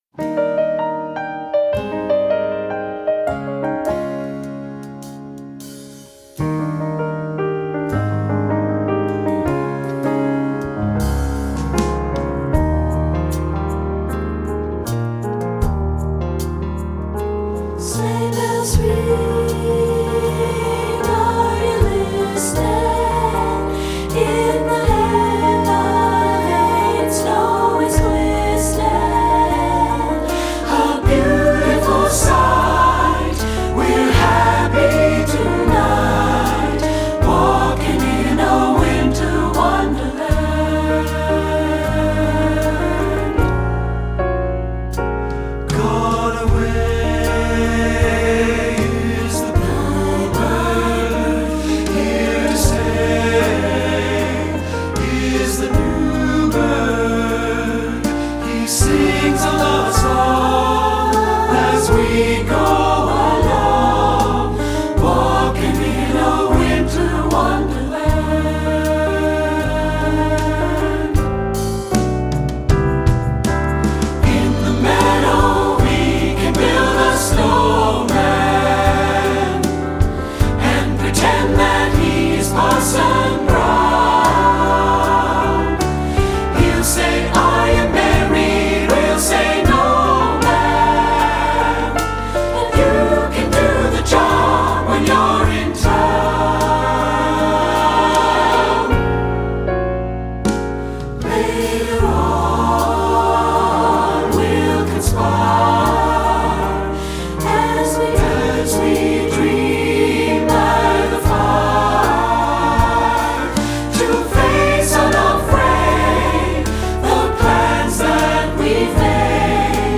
Guitar part:
Bass part:
Drumset part:
SATB